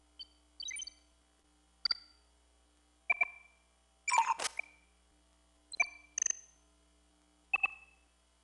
command_hum.ogg